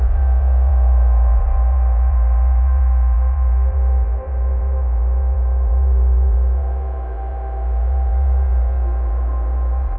Sound Effect